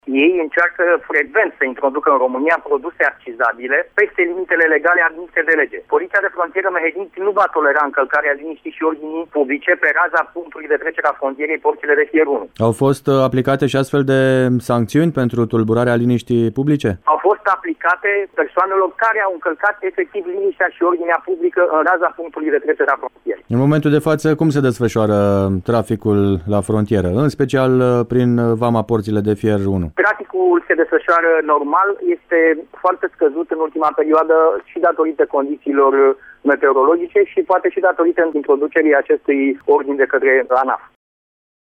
intervievat